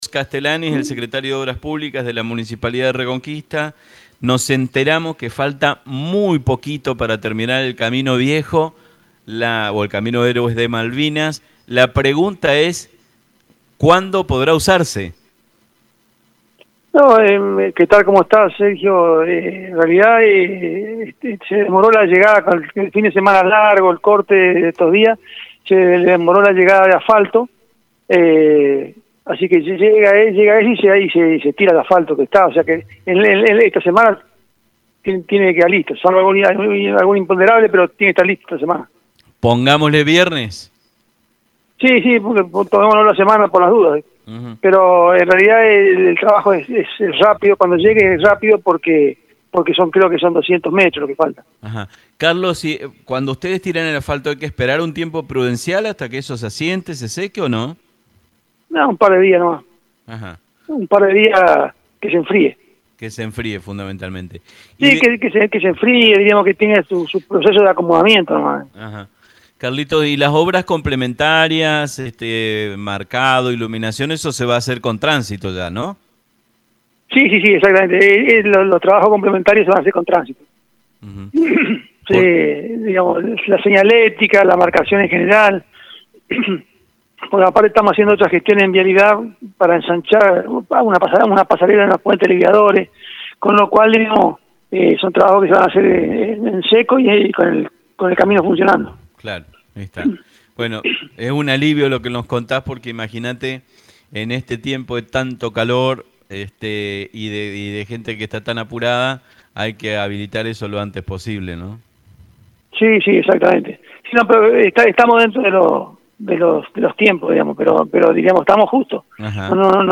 Carlos Castelani el secretario de Obras Publicas de la Municipalidad de Reconquista aseguro a Vivos y Sueltos, que en el trascurso de esta semana se habilitara el transito en el camino héroes de Malvinas.